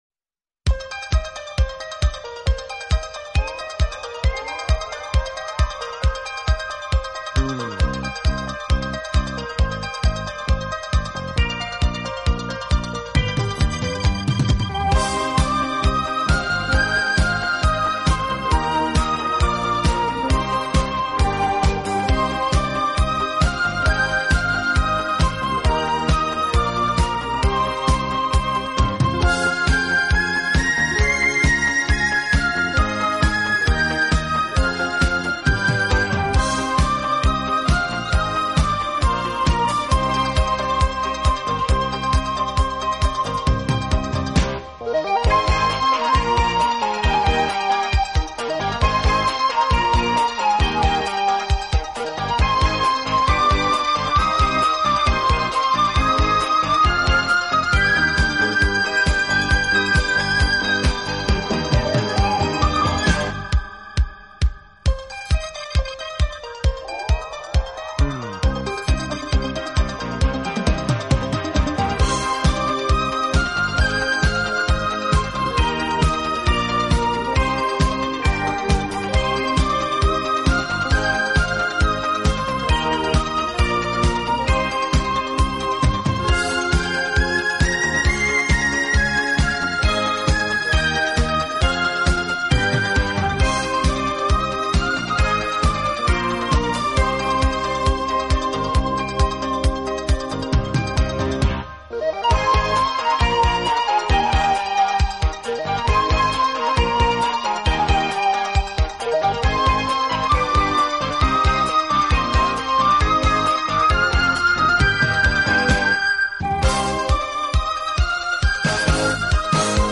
笛子都是主要乐器。
的音乐总是给人那幺大气、庄严和堂皇的感觉。